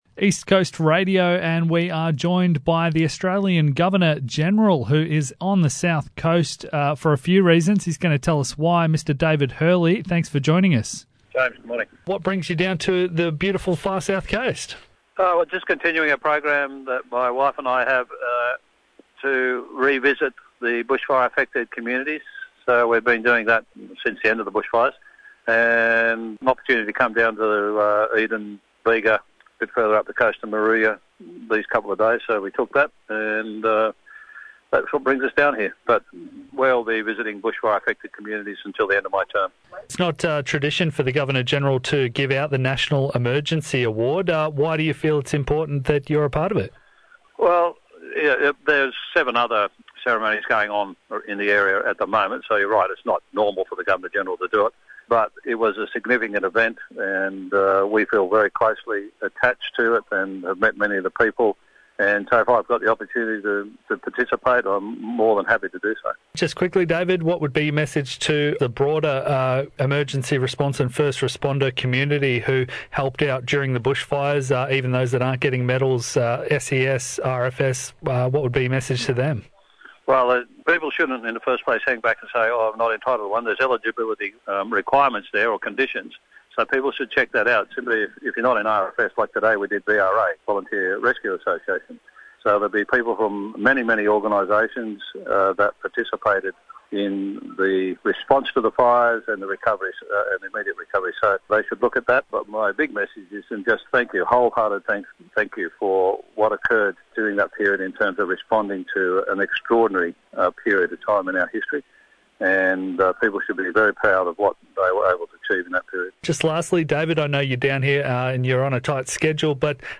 Governor General David Hurley spoke with East Coast Radio Journalist